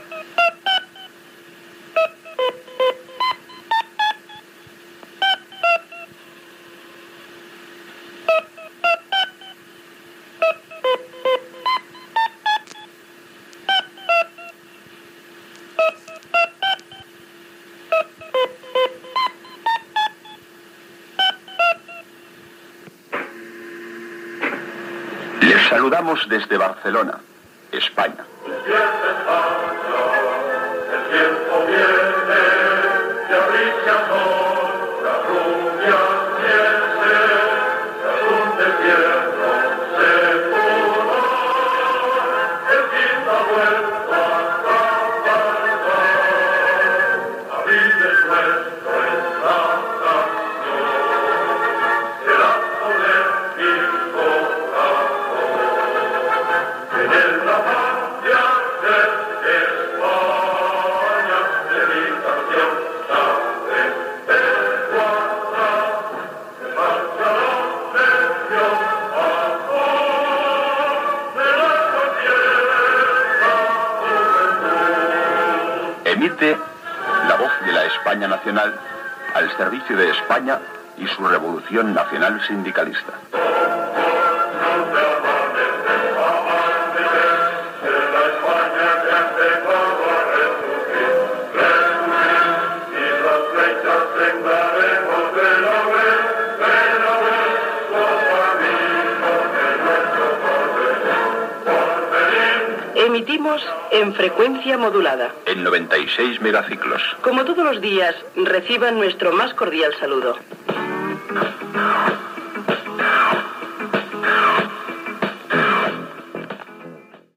Sintonia, inici de l'emissió amb la marxa
FM